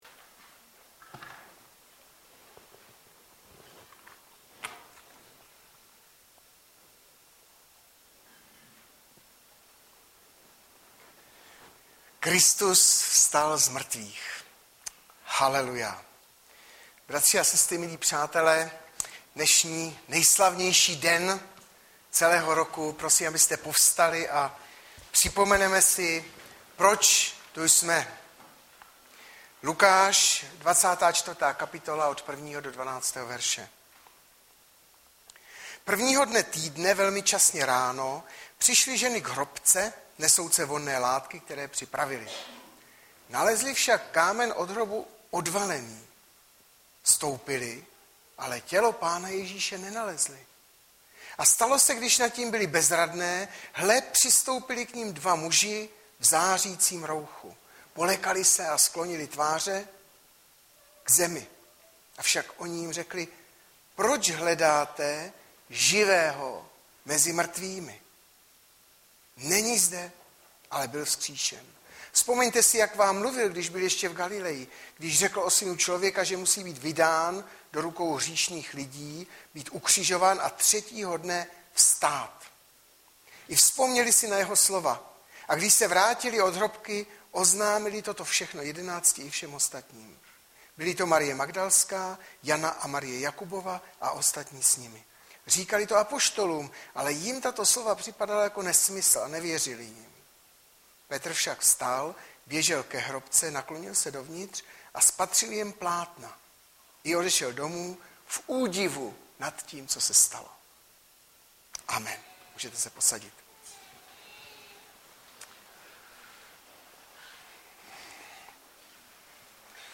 Hlavní nabídka Kázání Chvály Kalendář Knihovna Kontakt Pro přihlášené O nás Partneři Zpravodaj Přihlásit se Zavřít Jméno Heslo Pamatuj si mě  31.03.2013 - POHLED TĚ ZACHRÁNÍ - Jan 3,14-15 Audiozáznam kázání si můžete také uložit do PC na tomto odkazu.